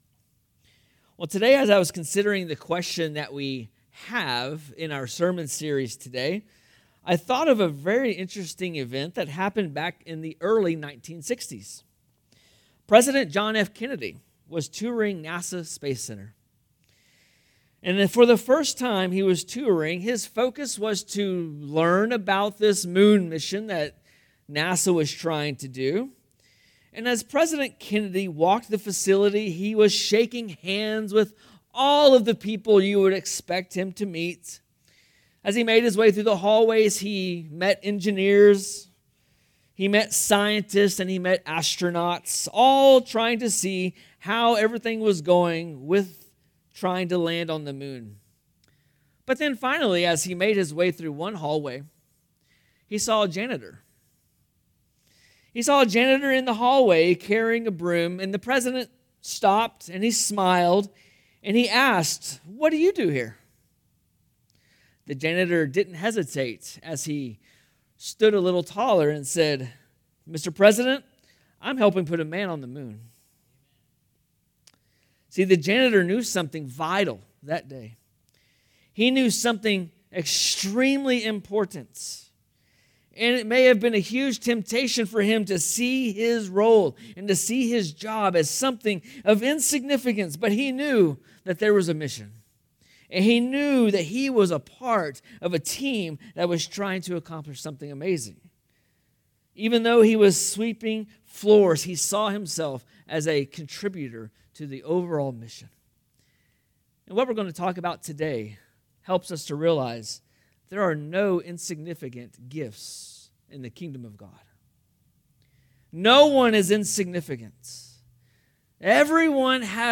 In this sermon we take a broad view of spiritual gifts, what spiritual gifts are and whether or not God is still using all the gifts.